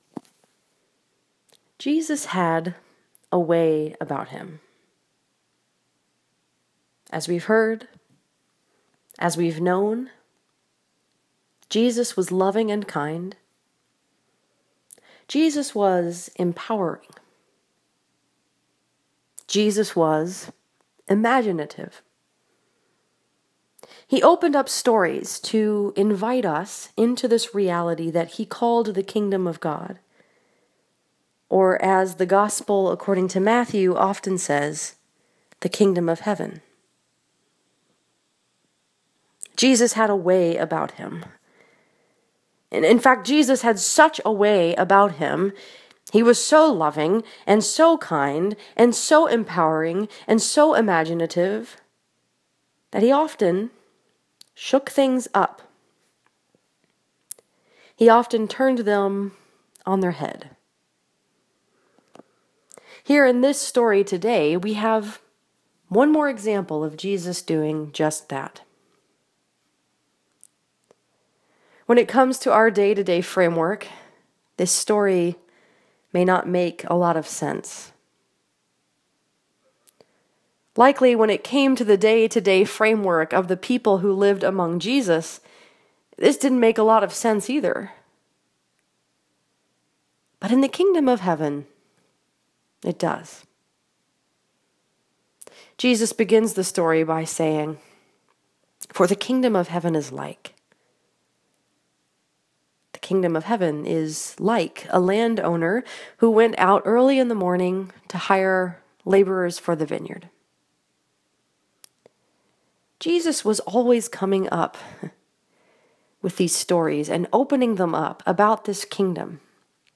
This sermon was preached at Kirk of Our Savior Presbyterian Church in Westland, Michigan and is based on Matthew 20:1-16.